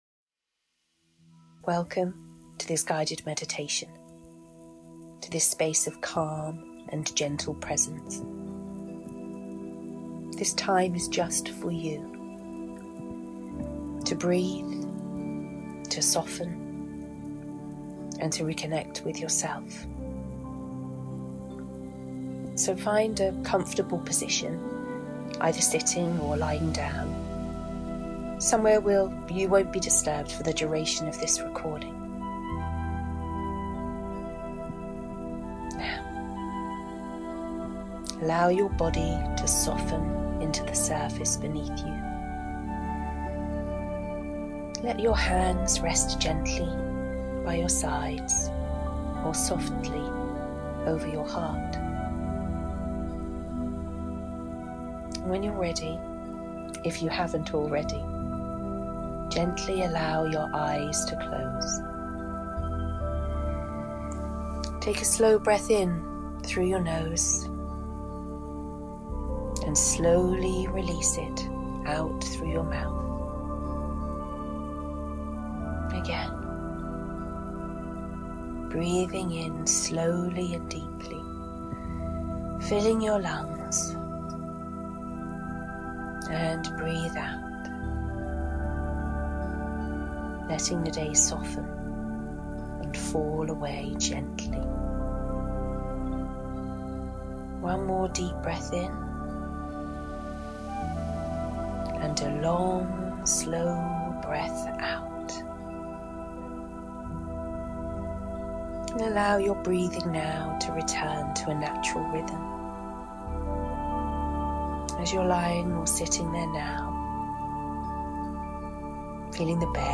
To celebrate, I am sharing with you a beautiful, guided meditation.